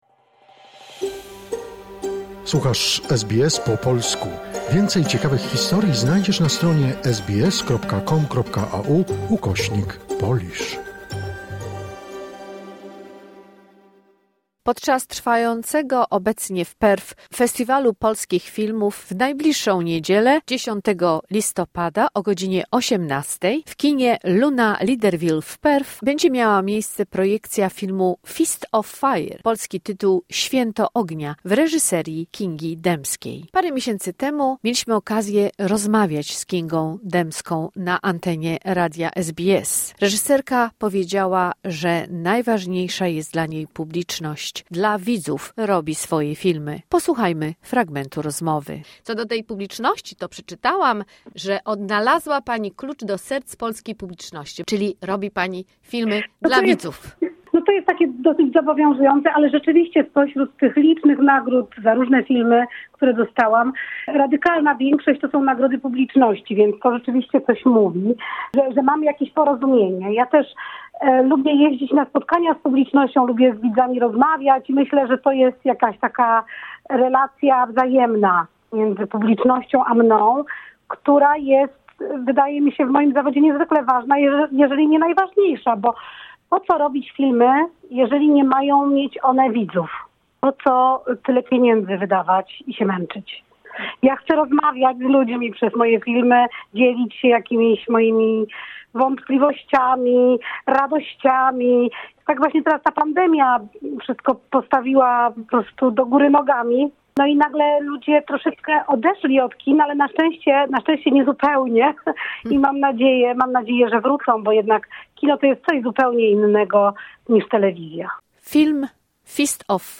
Posłuchajmy fragmentu rozmowy z reżyserką wielokrotnie nagrodzonego filmu "Święto ognia" Kingą Dębską.